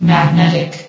CitadelStationBot df15bbe0f0 [MIRROR] New & Fixed AI VOX Sound Files ( #6003 ) ...
magnetic.ogg